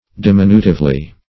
\Di*min"u*tive*ly\